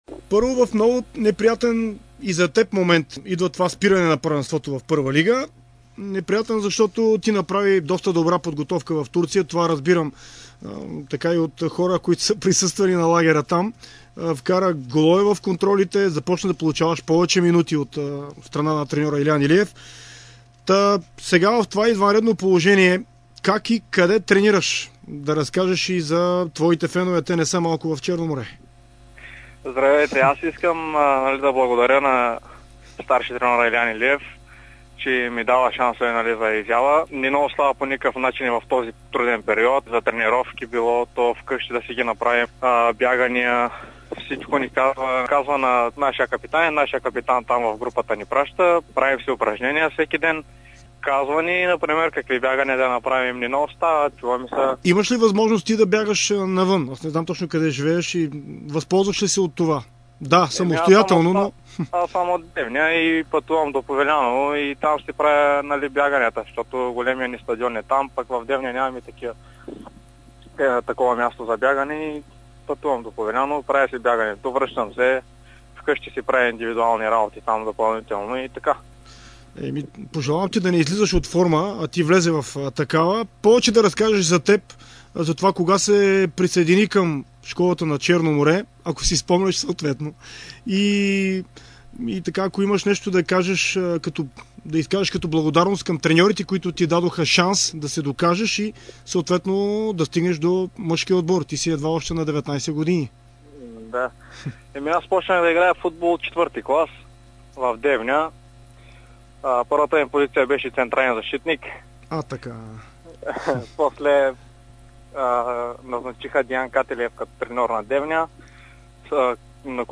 Призна, че за първи път дава интервю: